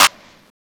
Passion Clap
Passion-Clap.wav